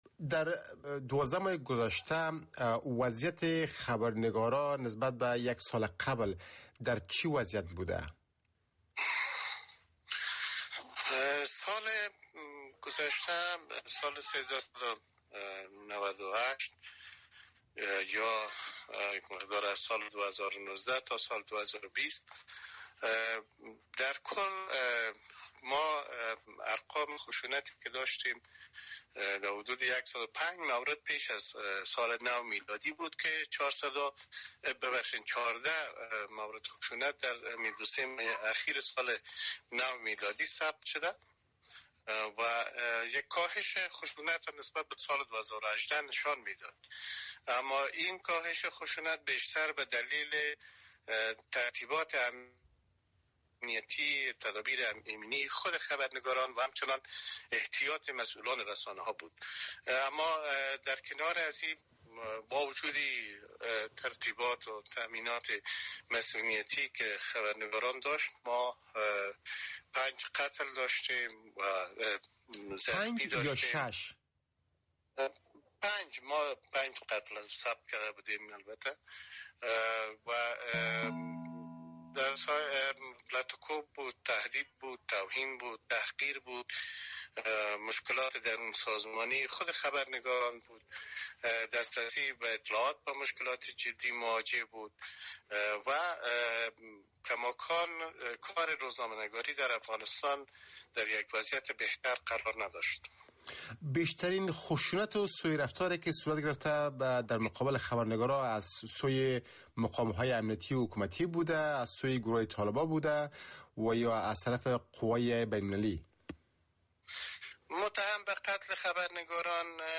شرح کامل مصاحبه